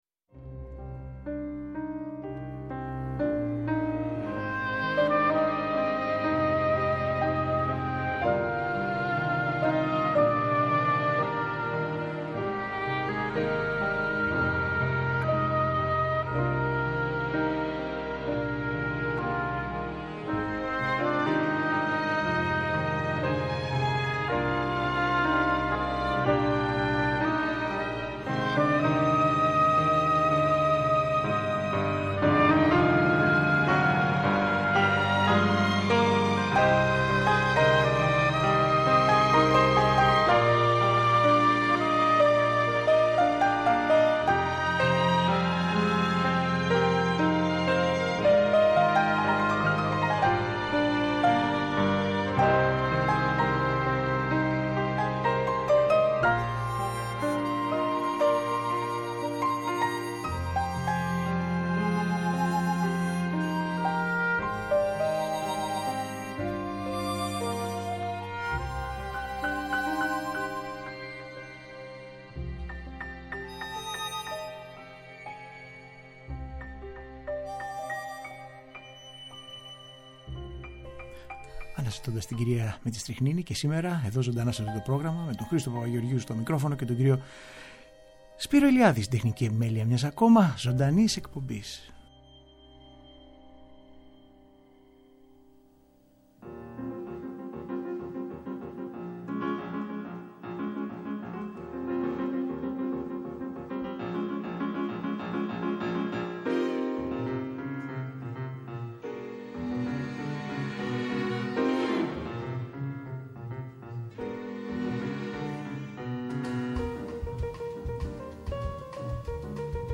Μια δροσερή Τζαζ πρόταση για το καλοκαίρι. Ευφάνταστες ενορχηστρώσεις και νέες συνθέσεις παιγμένες από κορυφαίους μουσικούς.